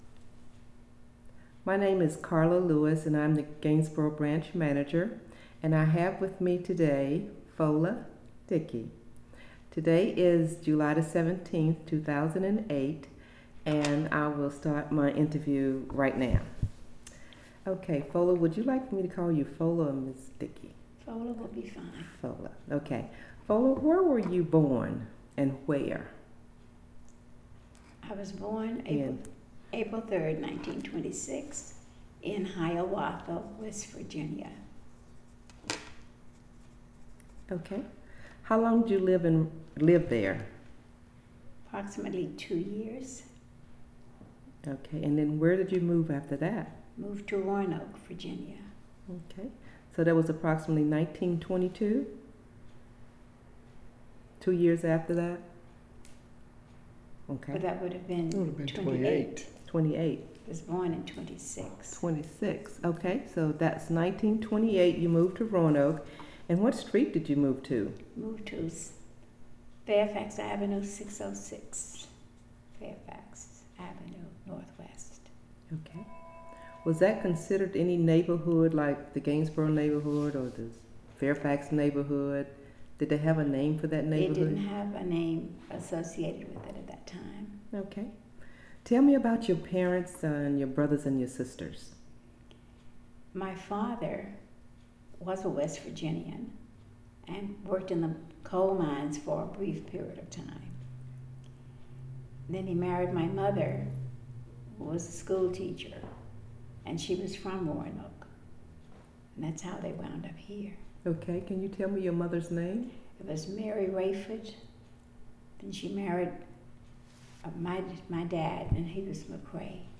Location: Gainsboro Branch Library
Neighborhood Oral History Project